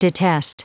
Prononciation du mot detest en anglais (fichier audio)